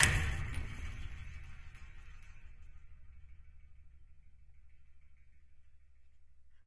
乐器是由金属弹簧从一个大的卡拉巴什壳上延伸出来的；录音是用一对土工话筒和一些KK;接触话筒录制的，混合成立体声。 动态用pp（软）到ff（大）表示；名称表示记录的动作。
标签： 音响 防撞 一抖 金属制品 打击乐 咂嘴
声道立体声